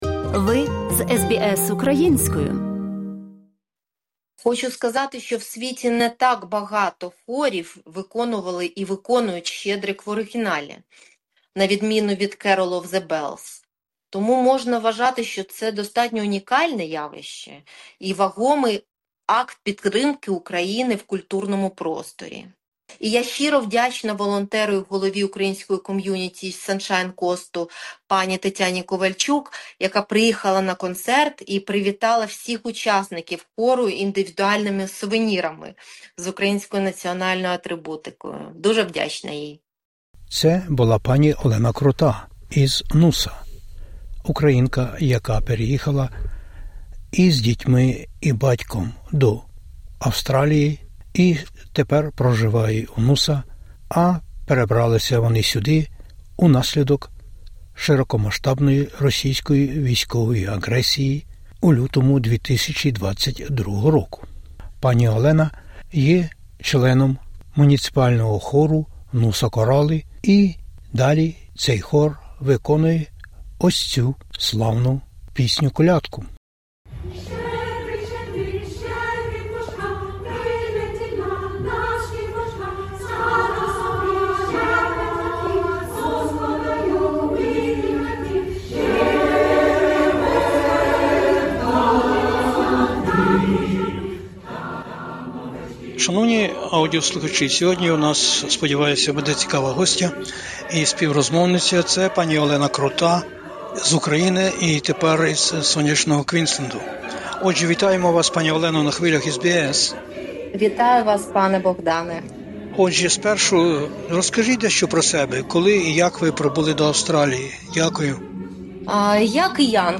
У розмові з SBS Ukrainian - про адаптацію в новій країні, про пошуки роботи, навчання задля удосконалення англійської, долучення до місцевого муніципального хору Noosa Chorale...